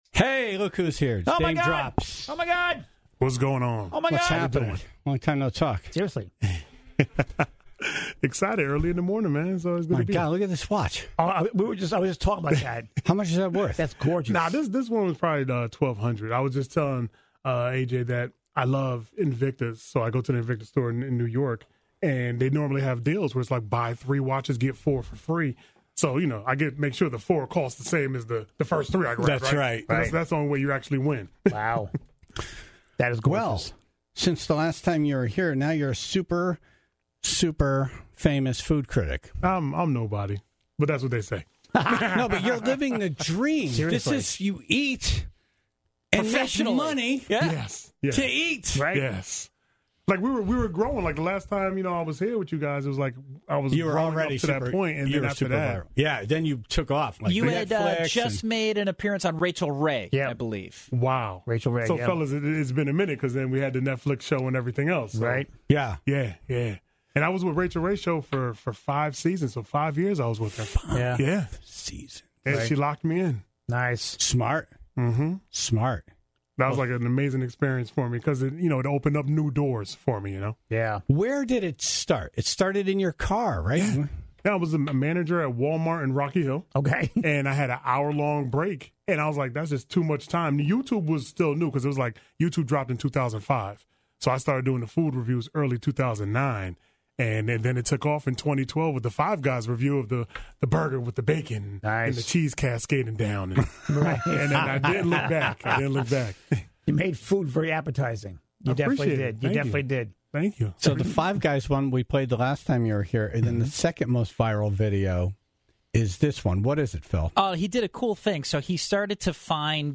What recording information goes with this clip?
Today, he's back in charge of his own content, and even did a live review of some Haven Hot Chicken sandwiches.